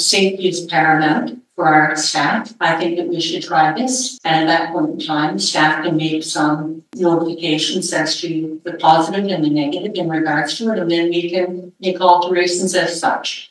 Councillor Isabel Anne McRae weighed in.